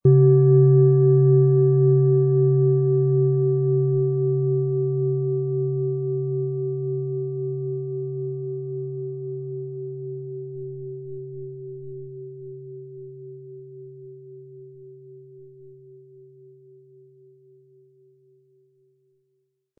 OM Ton
Es ist eine nach uralter Tradition von Hand getriebene Planetenton-Klangschale OM-Ton.
Wie klingt diese Planetenton-Klangschale OM-Ton?
Besonders schöne Töne zaubern Sie aus der Klangschale, wenn Sie sie sanft mit dem beiliegenden Klöppel anspielen.
MaterialBronze
VerwendungRuhe und Meditation